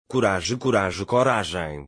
ttsmp3_P5xMBbW.mp3